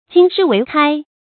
注音：ㄐㄧㄣ ㄕㄧˊ ㄨㄟˊ ㄎㄞ
金石為開的讀法